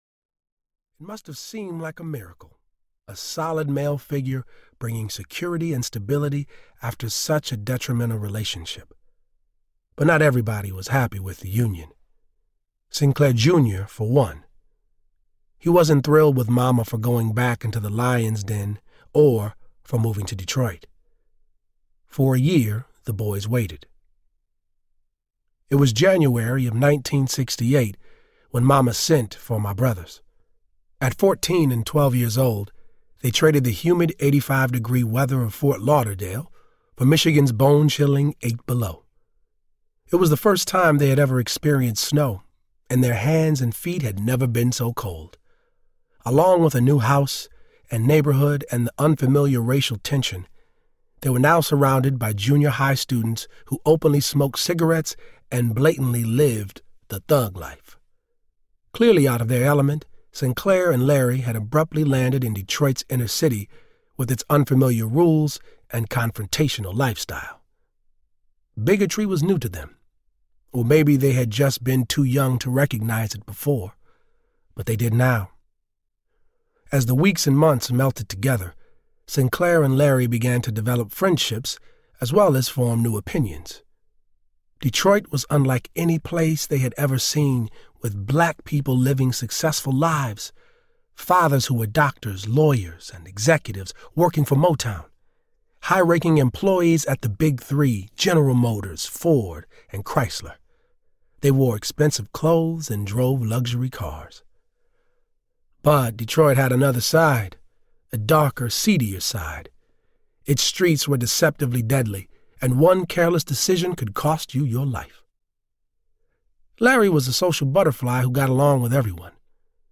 Cry Like a Man Audiobook
Narrator
5.53 Hrs. – Unabridged